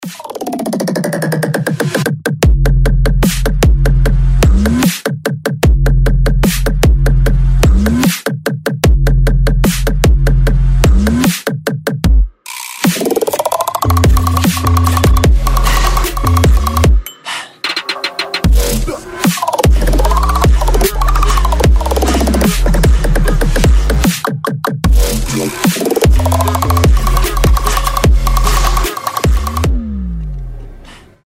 • Качество: 320, Stereo
громкие
жесткие
мощные
взрывные
пугающие
Супермощный hybrid trap